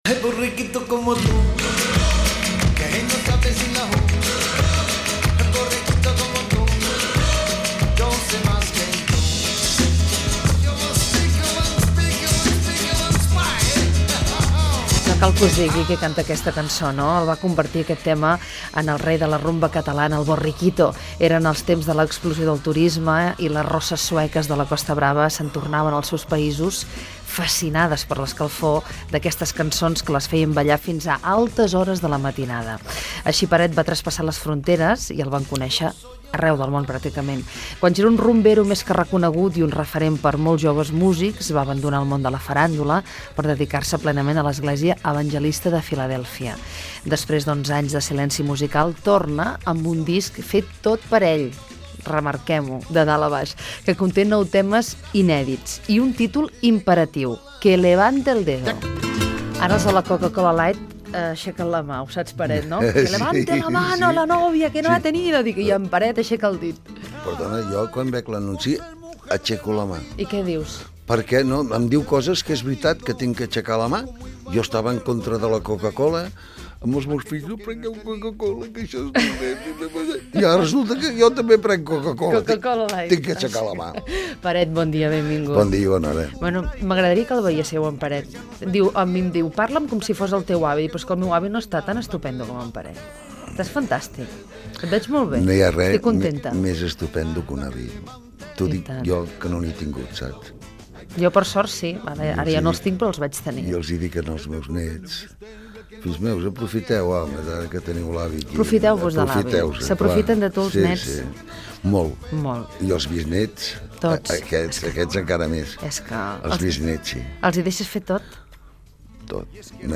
Fragment d'una entrevista al cantant Peret (Pere Pubill Calaf) sobre la seva trajectòria i el seu nou disc amb temes propis
Fragment extret de l'arxiu sonor de COM Ràdio